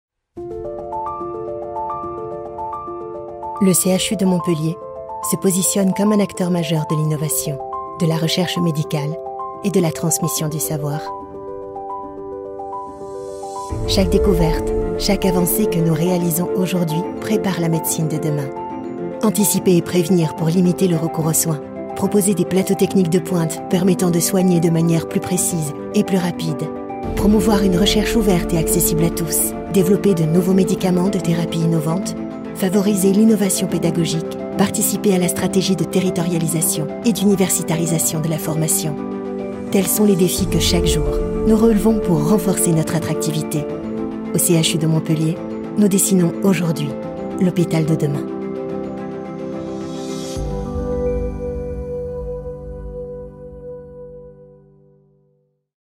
Narration
French voice over actress native from France, neutral accent.
My voice can be natural, sensual, dramatic, playful, friendly, institutional, warm and much more …
Mezzo-Soprano